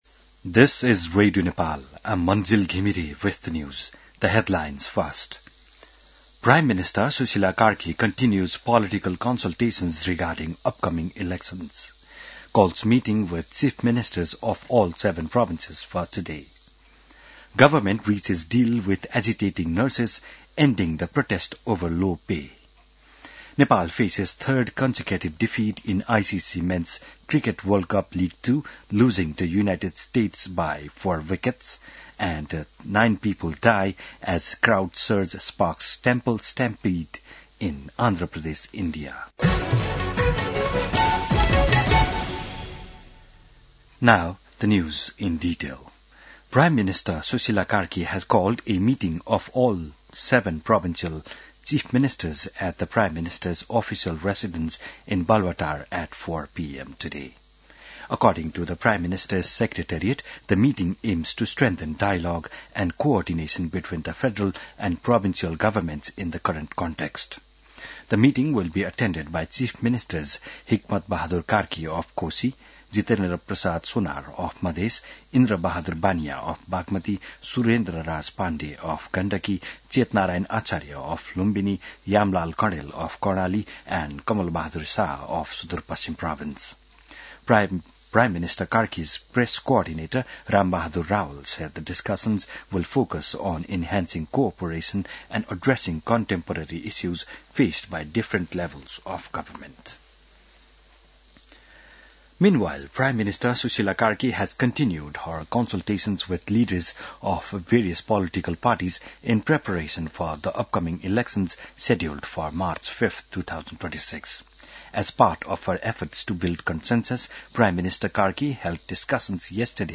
बिहान ८ बजेको अङ्ग्रेजी समाचार : २५ कार्तिक , २०८२